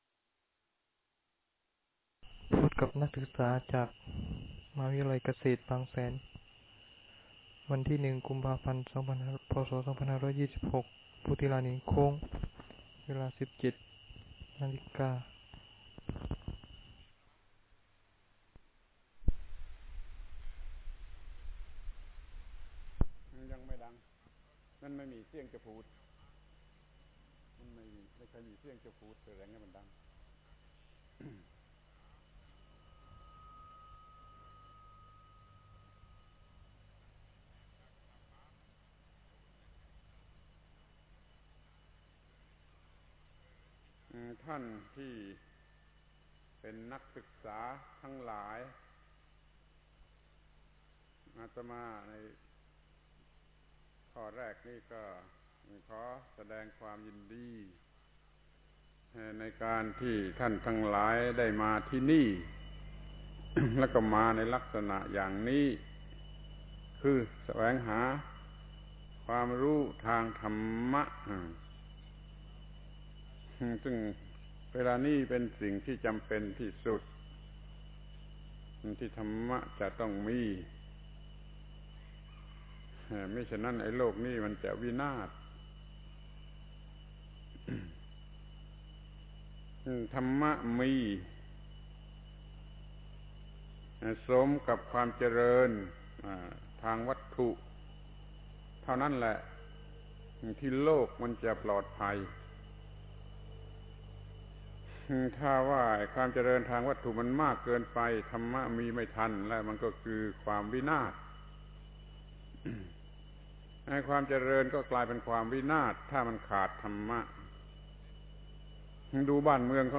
Title โอวาทแก่นักศึกษาจากมหาวิทยาลัยเกษตรศาสตร์ บางเขน กทม.